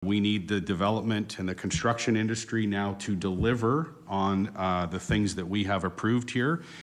At Monday’s council meeting Paul Carr said just four new residential building permits had been issued in January and February and that was not enough.